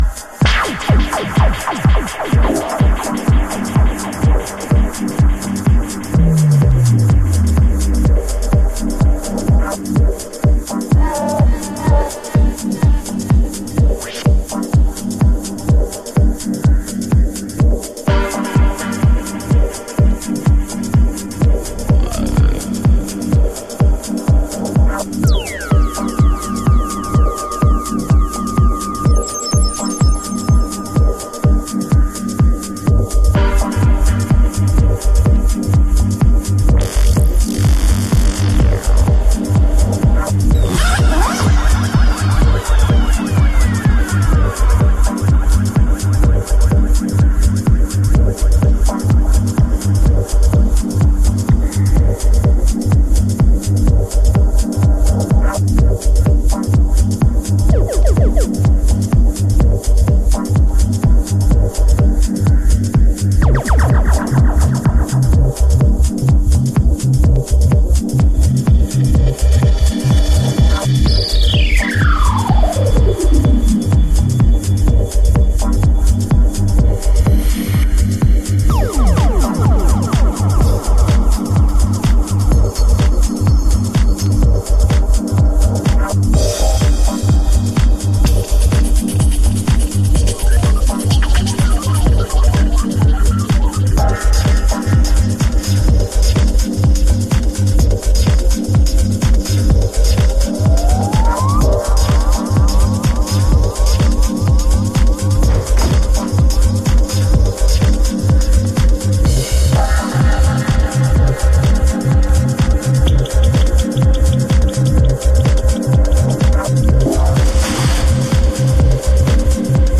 House / Techno
ビッグルーム映えもしそうなディープトリッピーダブテックトラック。